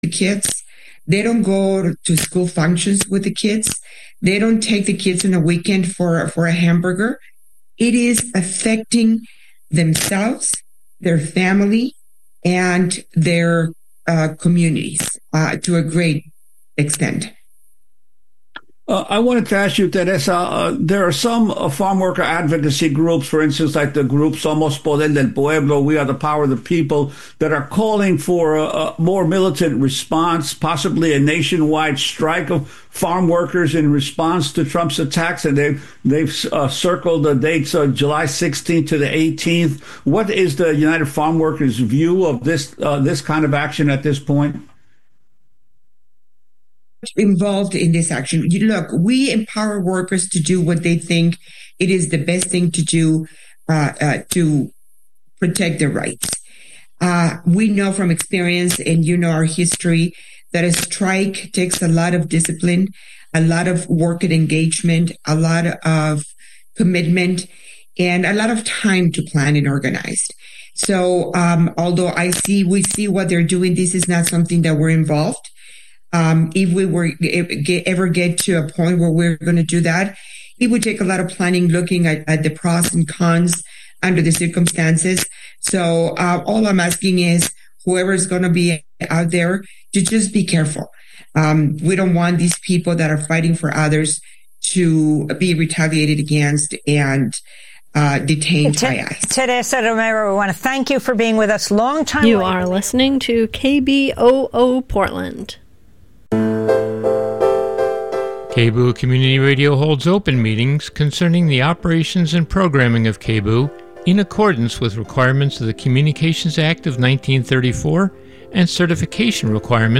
Hosted by: KBOO News Team
Non-corporate, community-powered, local, national and international news